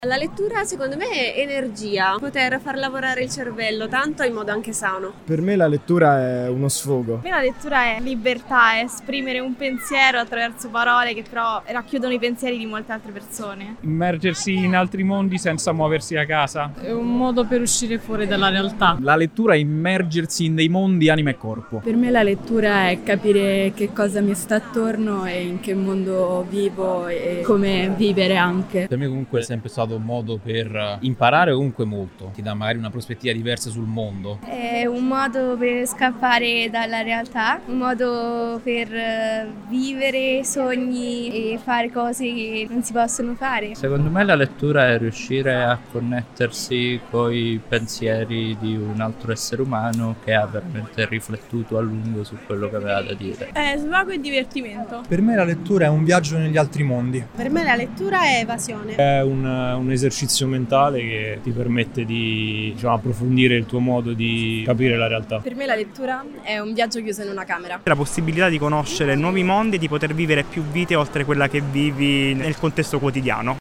Tra di loro moltissimi giovani, ai quali abbiamo rivolto una semplice domanda: che cosa è per voi la lettura? Ecco le voci degli “sfaticati”.